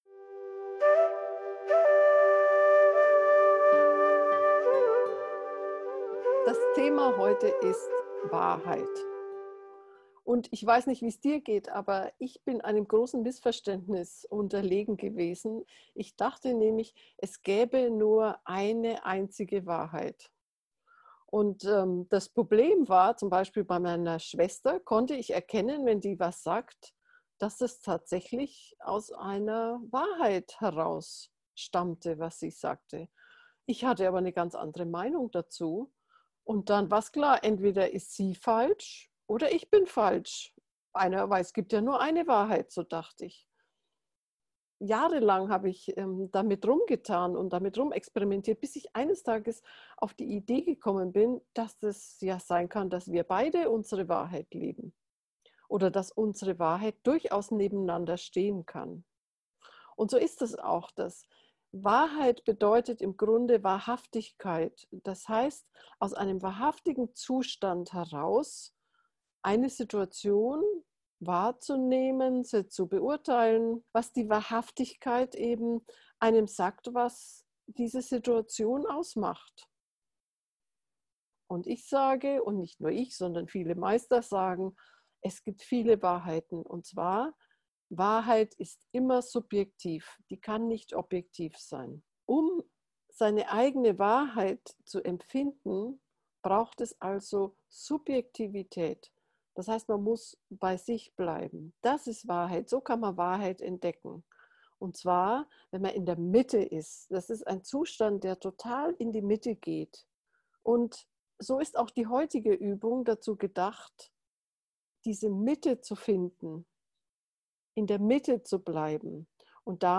Geführte Meditationen
Meditationsanleitung zur geführten Meditation